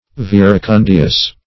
Search Result for " verecundious" : The Collaborative International Dictionary of English v.0.48: Verecundious \Ver`e*cun"di*ous\, a. Verecund.